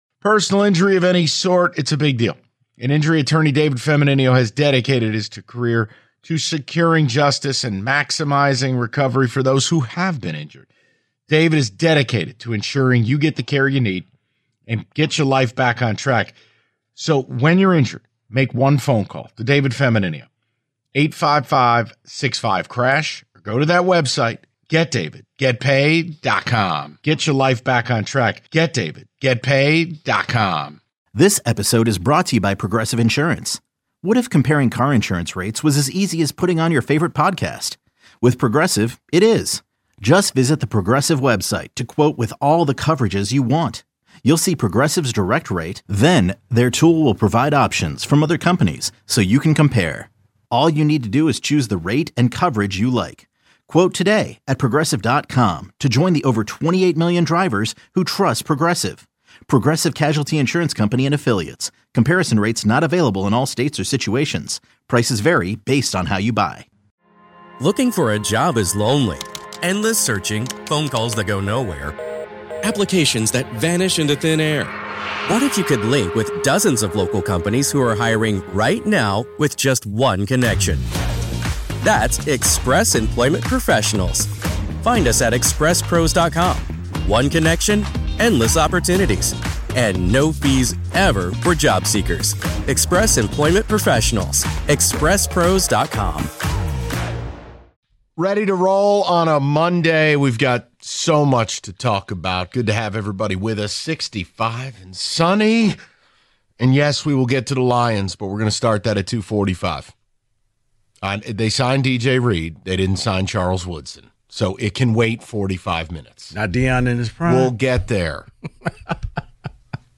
They take your calls and read your ticket texts on the topic before doing an NFL Free Agency Blitz to round out the hour.